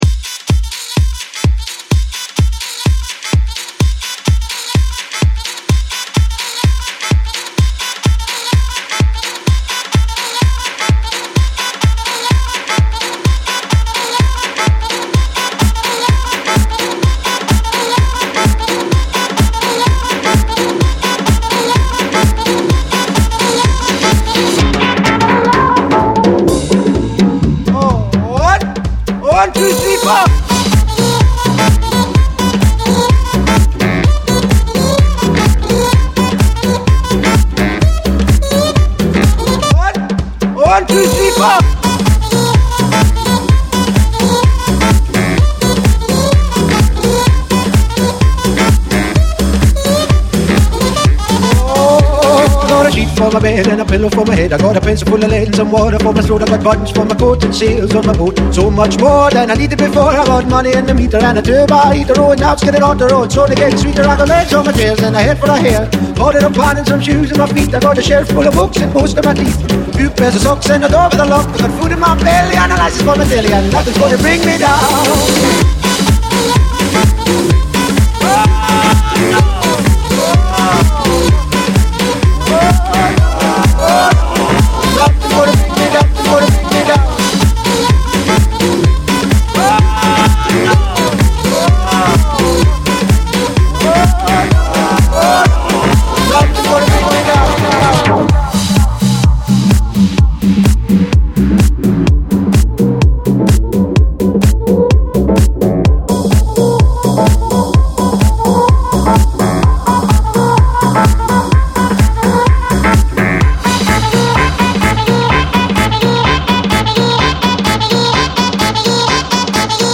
shuffling modern beats and vintage swing sounds
heavier wobble bass pushes things up a gear or two